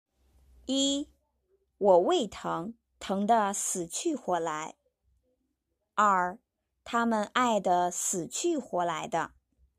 (sǐ qù huó lái)
Wǒ wèi téng, bìng dé sǐqùhuólái.
Tāmen ài de sǐqù huólái de.